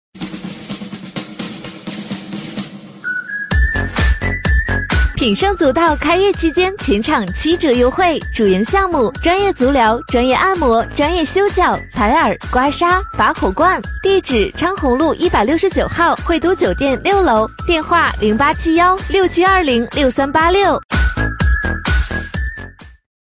【女57号促销激情】足疗店+音乐